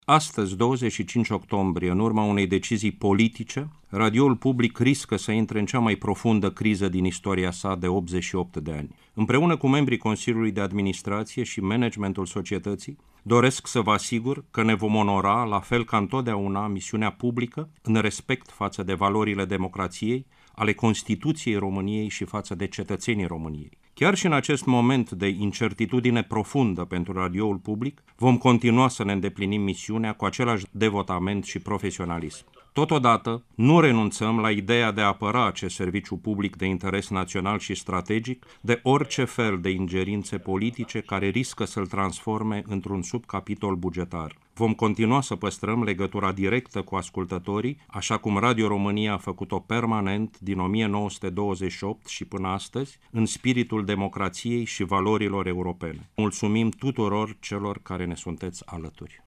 Mesajul Consiliului de Administraţie al Societăţii Române de Radiodifuziune a fost citit de preşedintele director general al Radio România, domnul Ovidiu Miculescu.